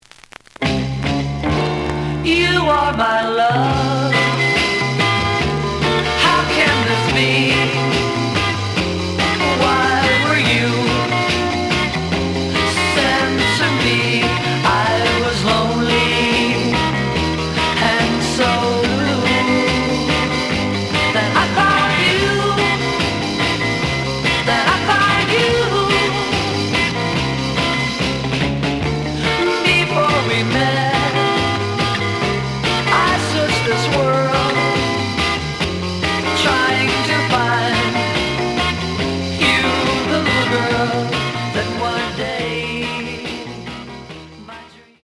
試聴は実際のレコードから録音しています。
The audio sample is recorded from the actual item.
●Genre: Rock / Pop